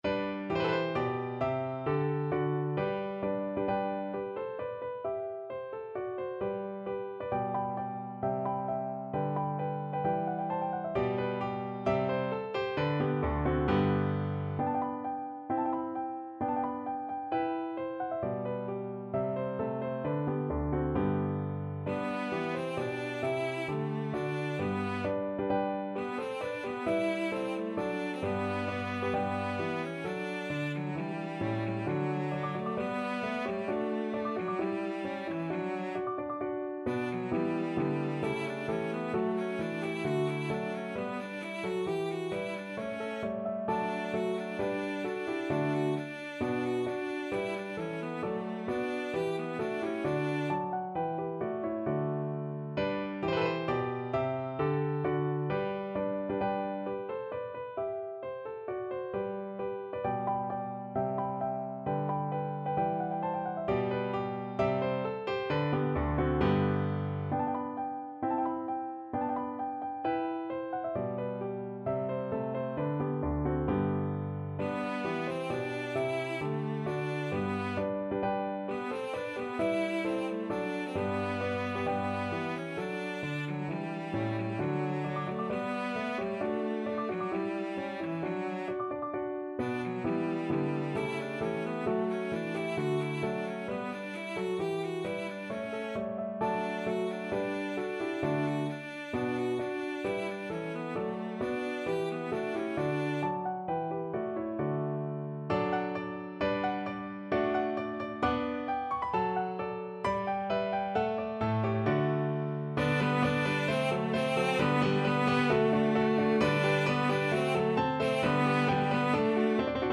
Cello version
Time Signature: 4/4
Score Key: G major (Sounding Pitch)
Style: Classical